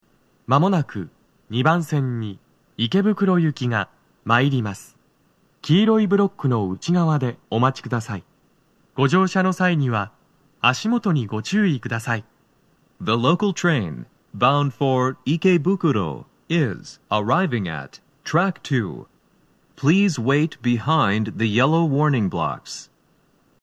男声
接近放送1
TOA天井型()での収録です。